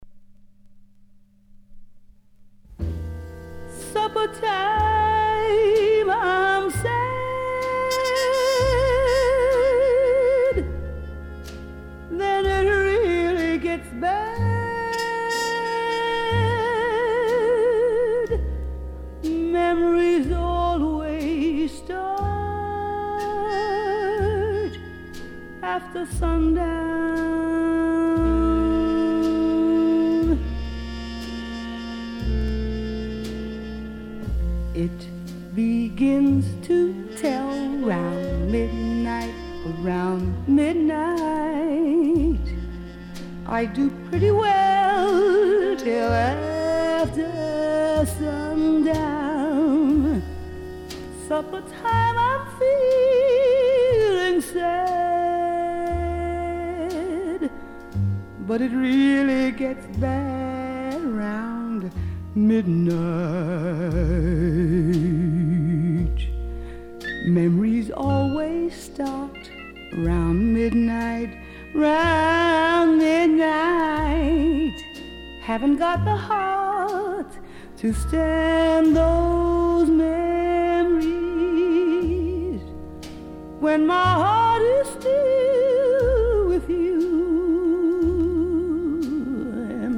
★ 毫不費力的音準掌控，拿捏得恰到好處的傲人嗓音。